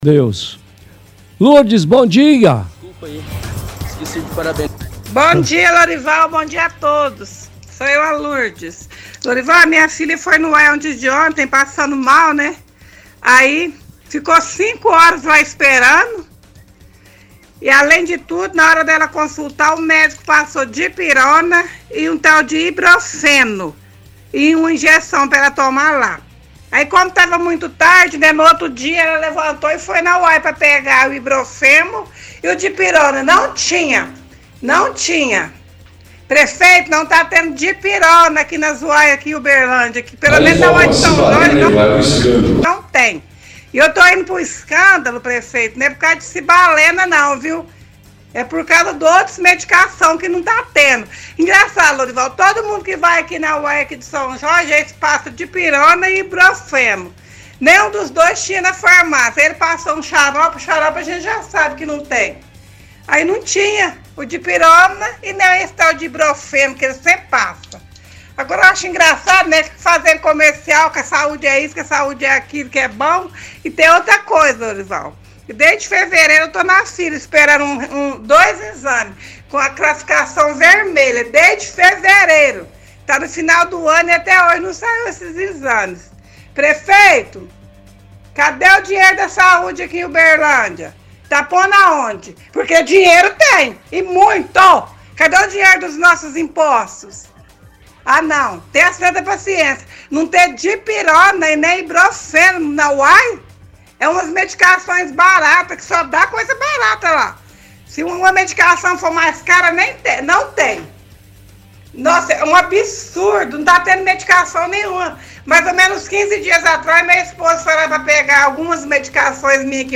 – Ouvinte fala que a saúde de Uberlândia tem muito a melhorar, pois tem muitas pessoas nas filas esperando por atendimentos.